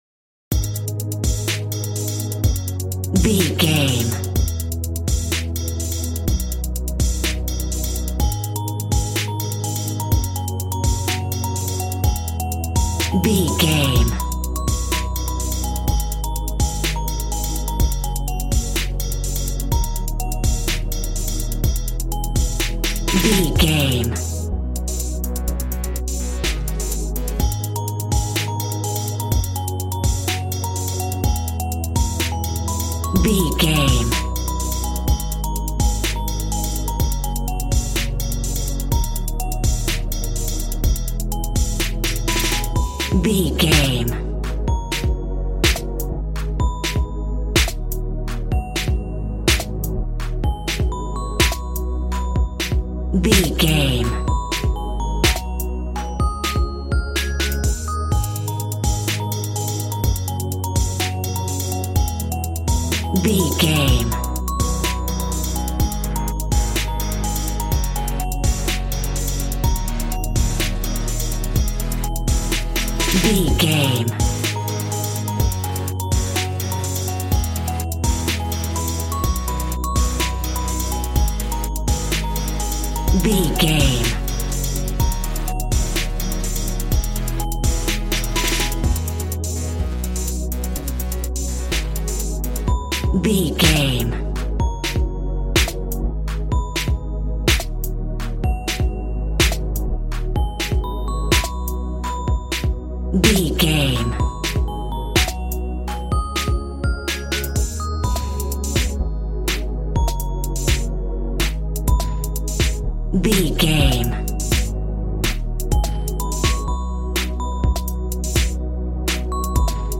Aeolian/Minor
A♭
calm
smooth
synthesiser
piano